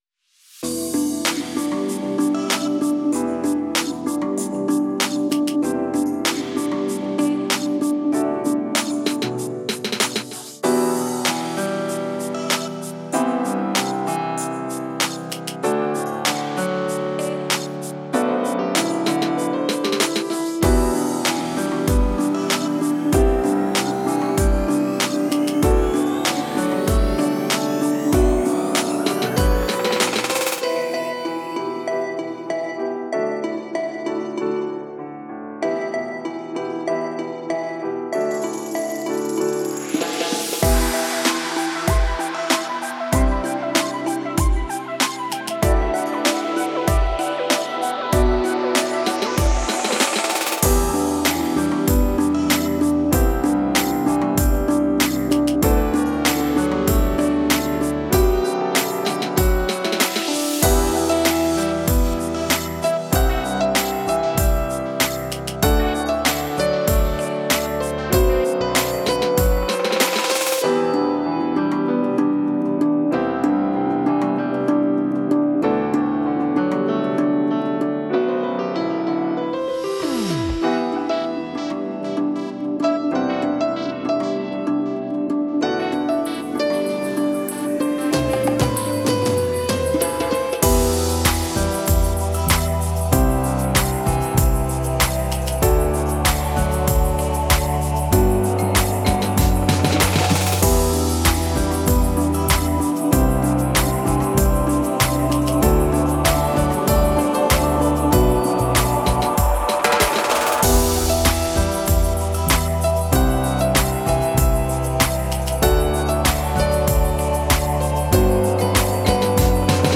/ Hip-Hop / Rap